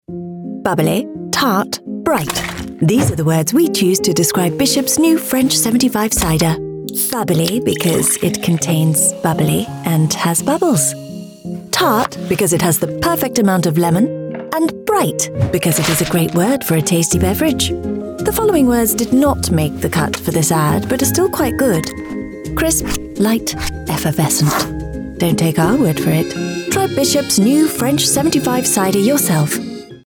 Female
English (British)
Television Spots
Bubbly, Bright, Funny
Words that describe my voice are Professional, Warm, Articulate.